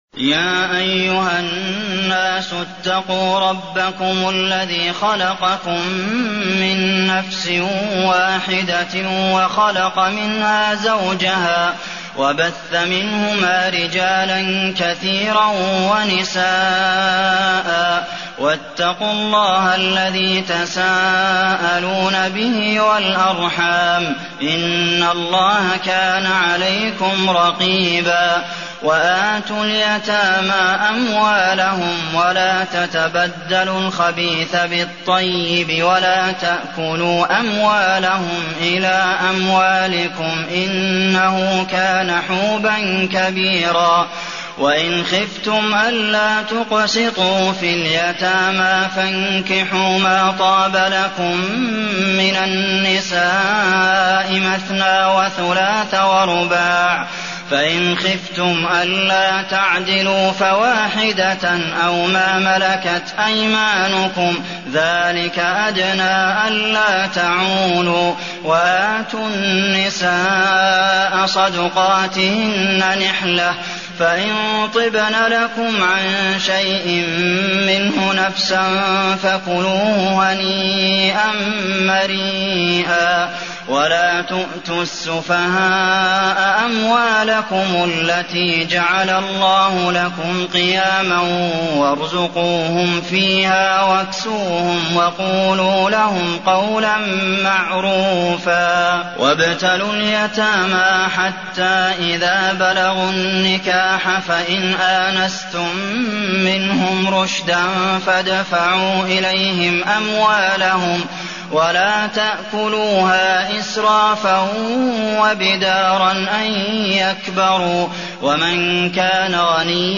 المكان: المسجد النبوي النساء